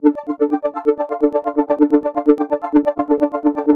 Superflutter Dm 128.wav